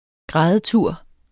Udtale [ ˈgʁaːðəˌtuɐ̯ˀ ]